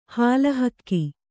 ♪ hāla hakki